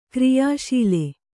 ♪ kriyāśile